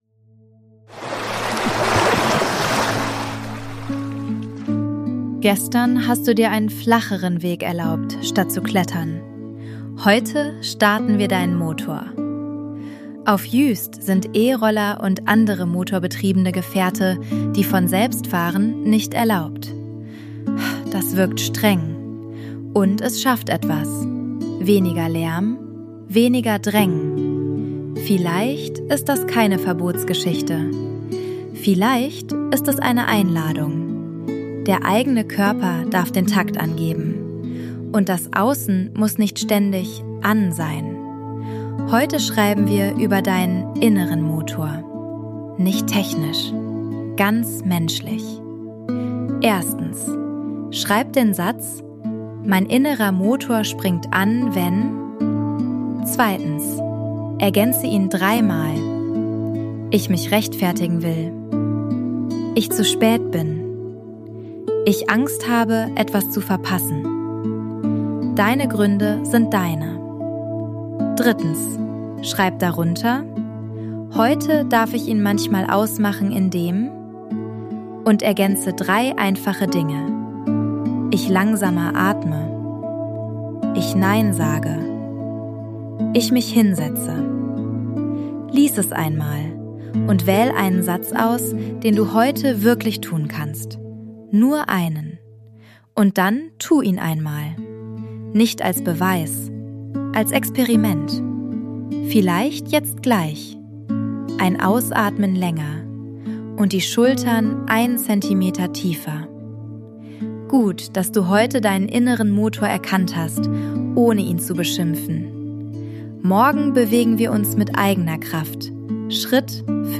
Juist Sounds & Mix: ElevenLabs und eigene Atmos